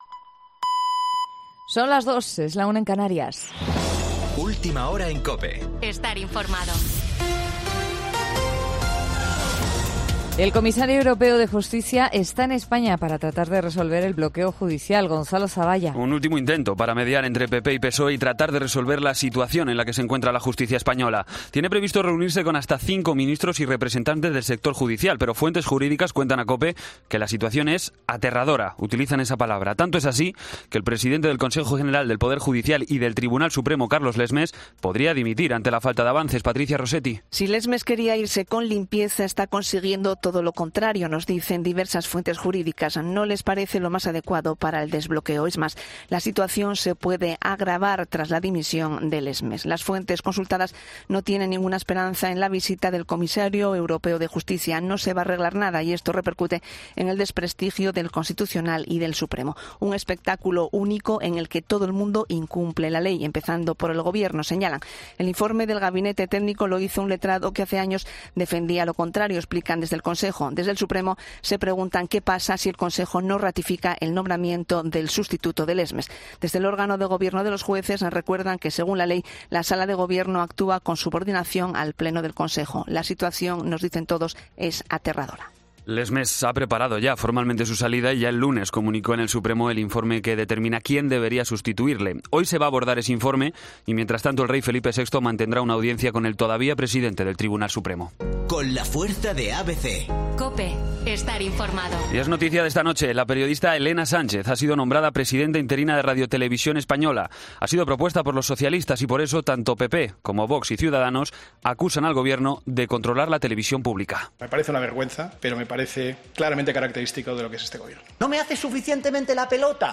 Boletín de noticias COPE del 28 de septiembre a las 02:00 hora